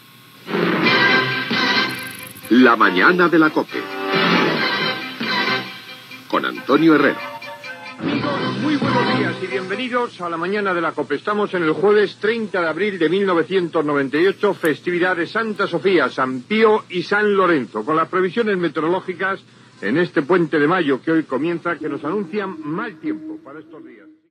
Careta del programa, data, sants del dia, avís de mal temps.
Informatiu